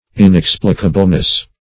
Search Result for " inexplicableness" : The Collaborative International Dictionary of English v.0.48: Inexplicableness \In*ex"pli*ca*ble*ness\, n. A state of being inexplicable; inexplicability.
inexplicableness.mp3